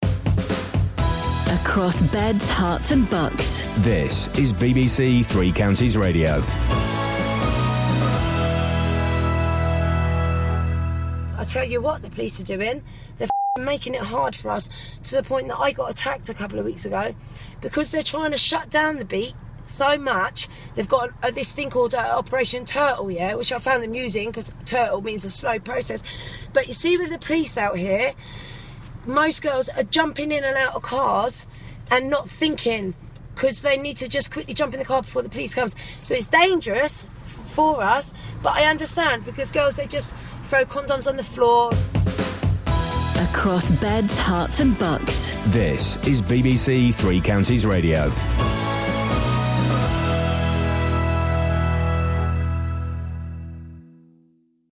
A prostitute in the High Town area of Luton talks about consequences of police action following complaints from local residents of anti-social behaviour.